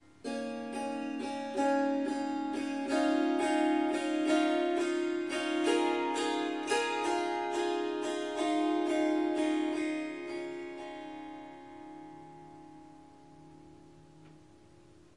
Swarmandal印度竖琴曲谱 " 竖琴轻柔的戏剧性曲谱3
这个奇妙的乐器是Swarmandal和Tampura的结合。
它被调到C sharp，但我已经将第四个音符（F sharp）从音阶中删除了。
一些录音有一些环境噪音（鸟鸣，风铃）。